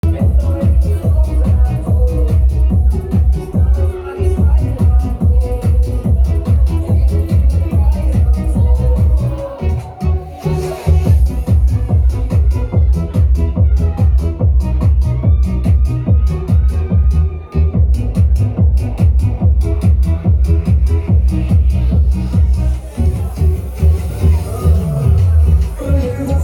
Dance / klubowa - Muzyka elektroniczna
Dance / klubowa
Moi mili, słyszałem dzisiaj w klubie taki kawałek, niestety Dj zapytany o tytuł powiedział, że "nie pamięta" a przecież gra to z playlisty.